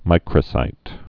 (mīkrə-sīt)